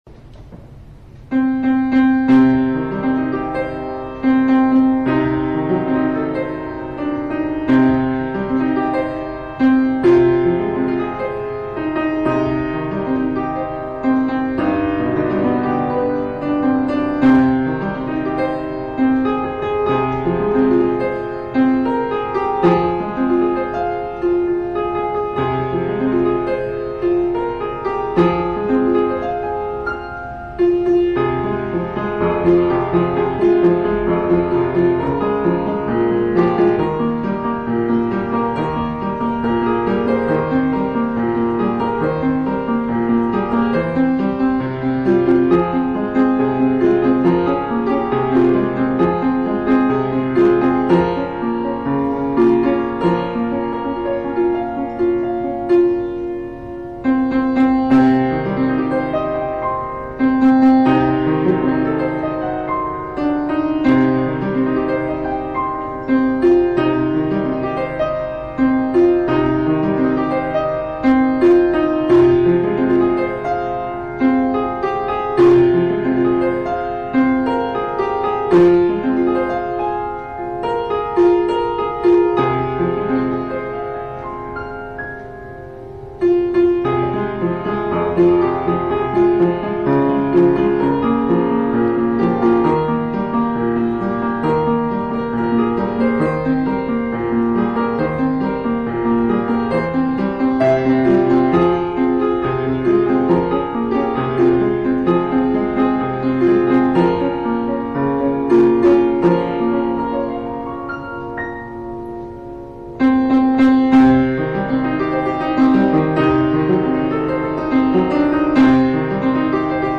שליטה יפה בכלי.